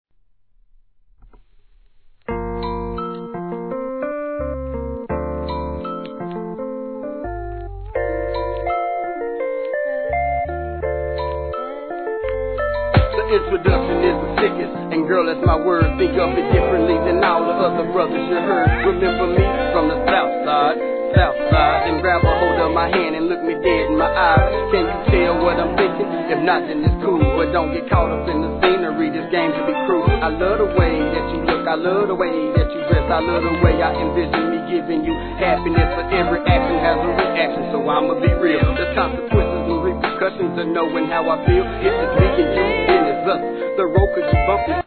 G-RAP/WEST COAST/SOUTH
メランコリンな上音が印象的な好MIDファンク!!